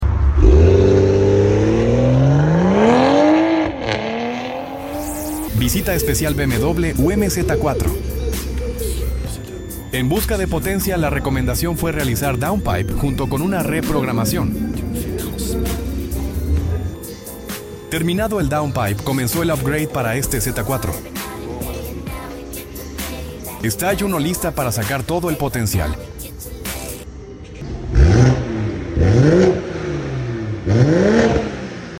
✅ Sonido más agresivo y deportivo